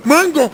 hitsound_retro3.wav